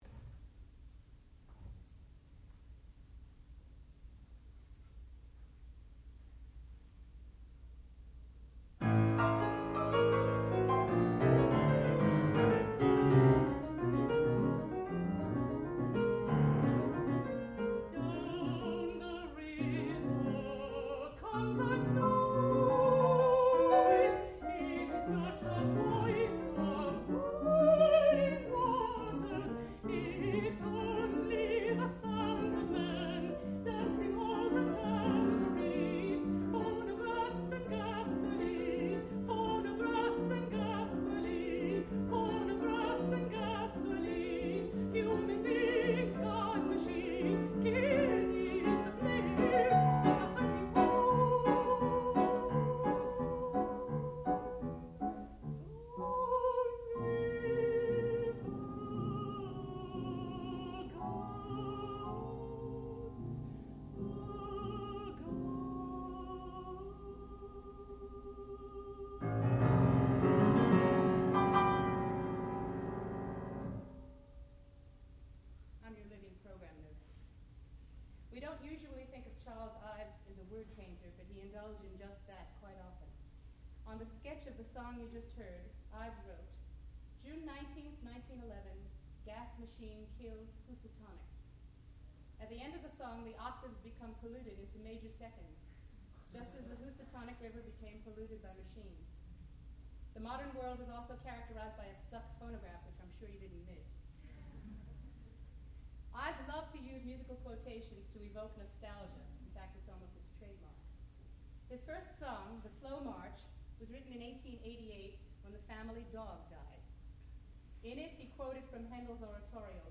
sound recording-musical
classical music
mezzo-soprano
piano